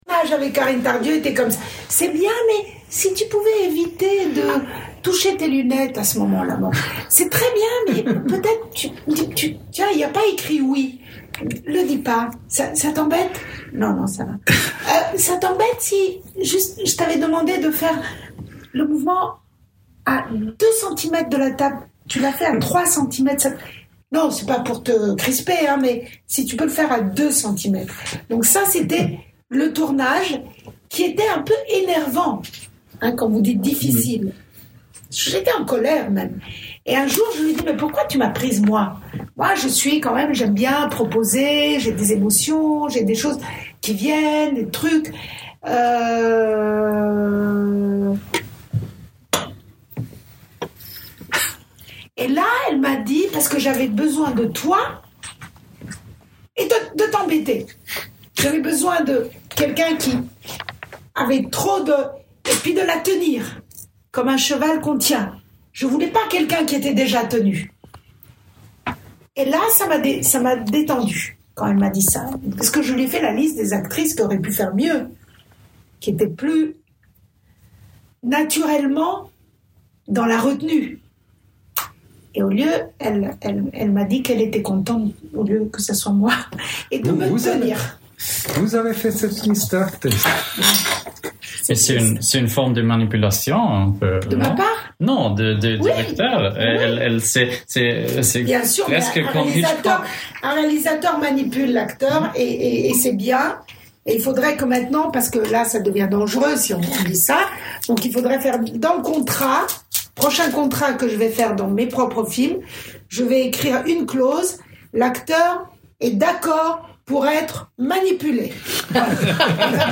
Rencontre.